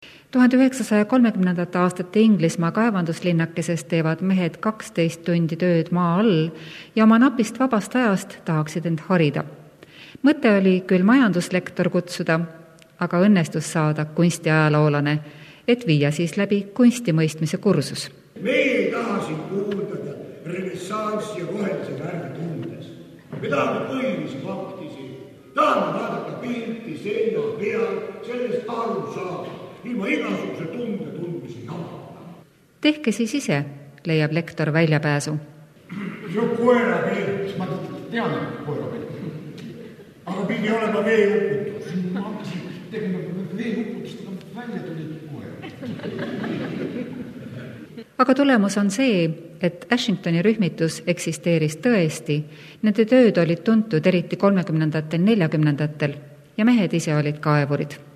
The recording comes from ERR (Eesti Rahvusringhääling).